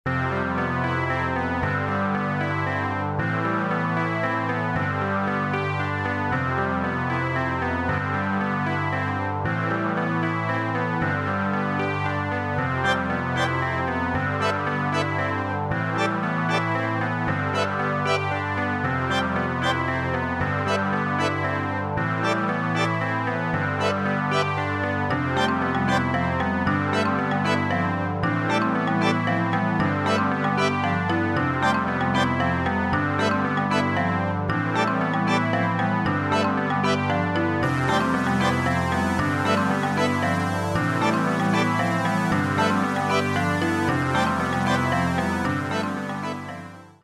ジャンル（沖縄、クラブ、アンビエント）